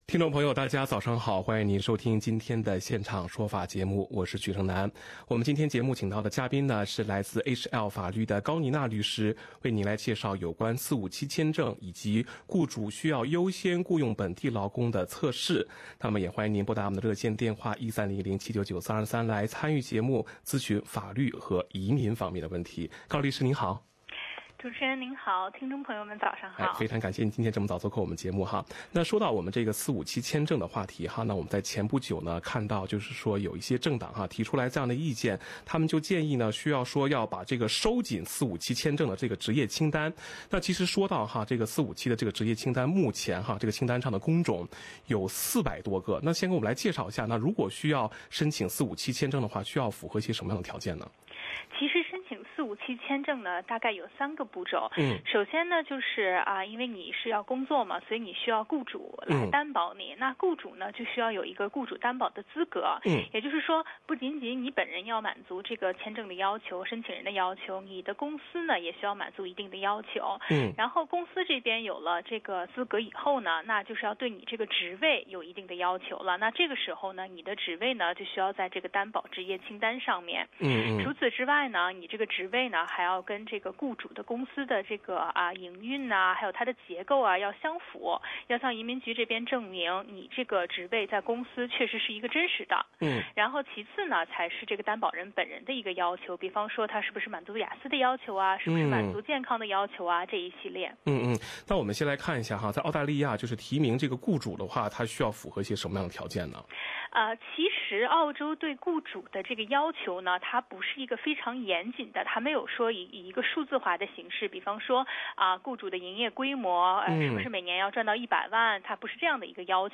《现场说法》听众热线节目，每周二上午8点半播出。